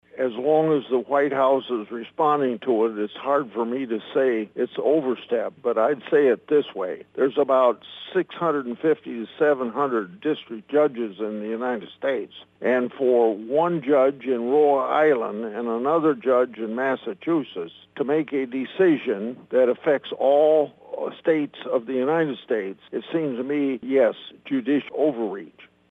Grassley made his comments this (Monday) afternoon during his weekly Capitol Hill Report with Iowa reporters.